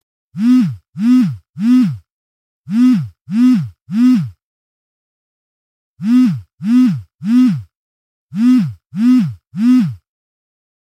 Вибрация мобильного телефона
Отличного качества, без посторонних шумов.
238_vibraciya.mp3